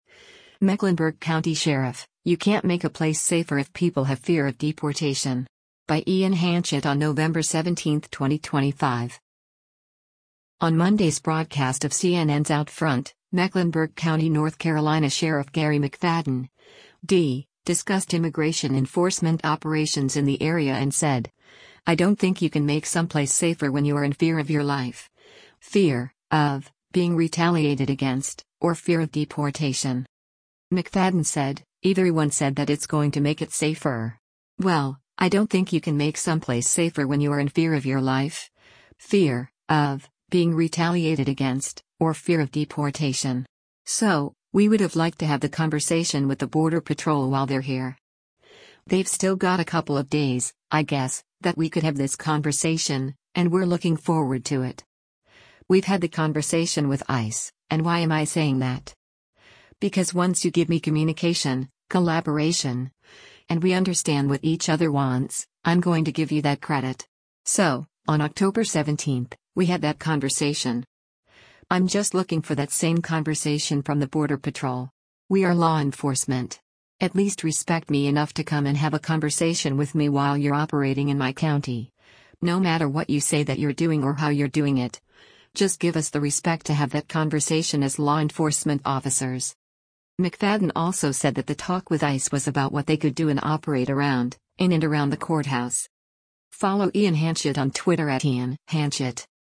On Monday’s broadcast of CNN’s “OutFront,” Mecklenburg County, NC Sheriff Garry McFadden (D) discussed immigration enforcement operations in the area and said, “I don’t think you can make someplace safer when you are in fear of your life, fear [of] being retaliated against, or fear of deportation.”